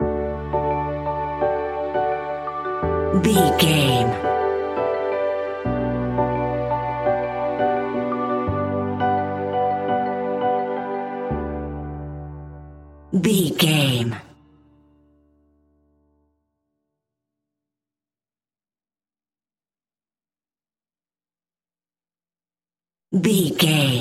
Ionian/Major
D
pop
pop rock
indie pop
fun
energetic
uplifting
cheesy
upbeat
groovy
guitars
bass
drums
piano
organ